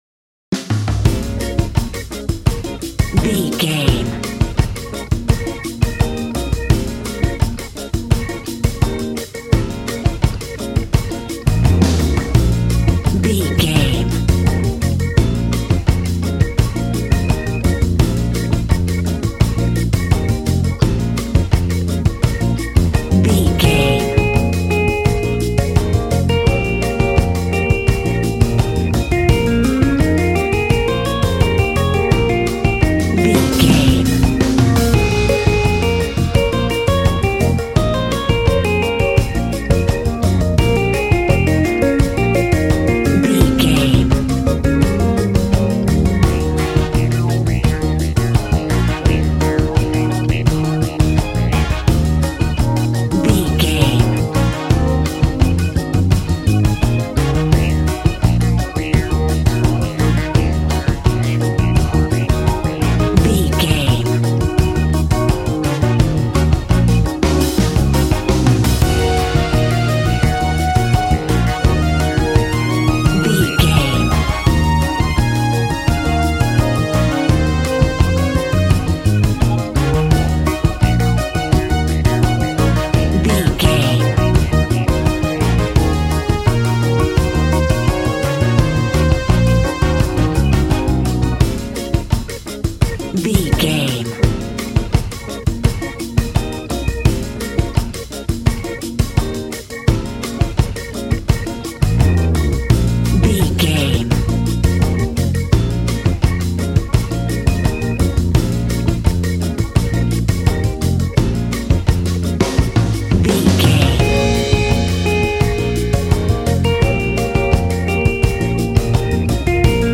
Aeolian/Minor
B♭
relaxed
smooth
synthesiser
drums
80s